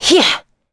Hilda-Vox_Attack2_b.wav